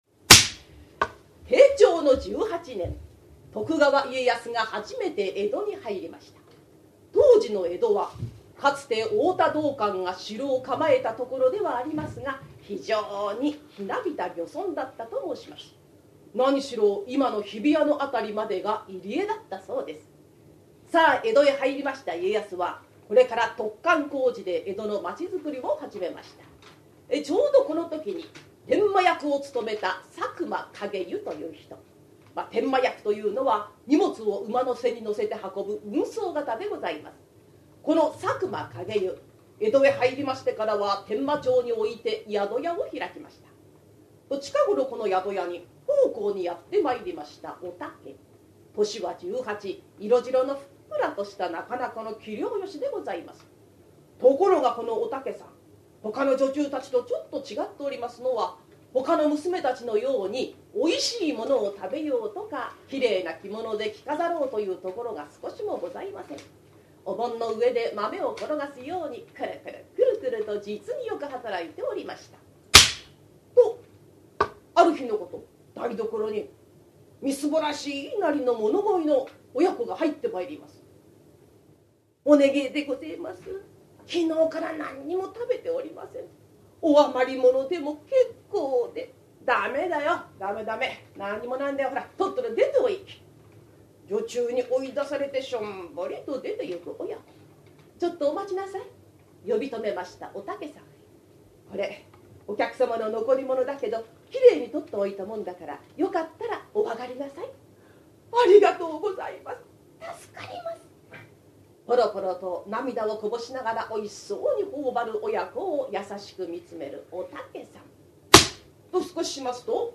ハリセンで釈台を叩き「パパン」という音を響かせて調子良く語る、江戸時代から伝わる日本伝統の話芸「講談」。講談協会に所属する真打を中心とした生粋の講談師たちによる、由緒正しき寄席で行われた高座を録音した実況音源！